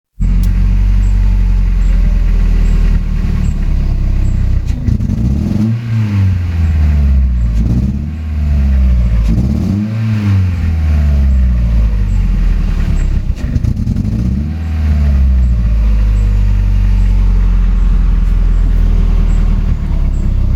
Quand je démarre ma voiture, mon moteur ne tourne pas rond, c'est comme si il tournait sur 3 cylindre, comme si un injecteur ne fonctionnait pas, quelque chose n'est pas synchro, quand j'appuie sur la pédale d'accélérateur on s'appercoit que ce n'est pas le bruit d'un moteur qui tourne bien, à écouter la bande son ici